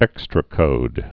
(ĕkstrə-kōd)